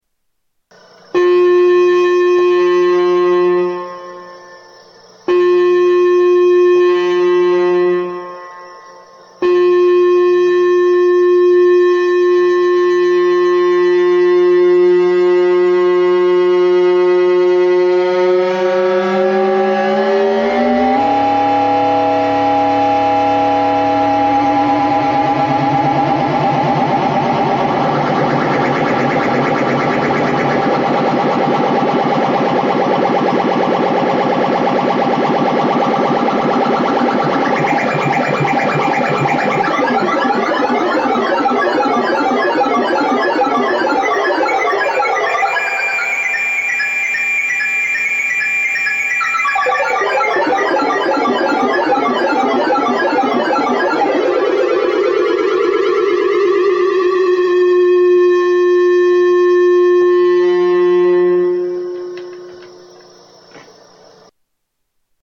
EML 101 Oscillator Noodle
Tags: Sound Effects EML ElectroComp 101 EML101 ElectroComp 101 Synth Sounds